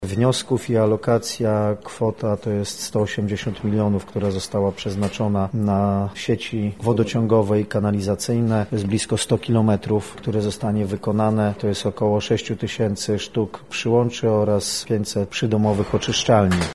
Jarosław Kwasekmp3 – mówi Jarosław Kwasek, Członek Zarządu Województwa Lubelskiego.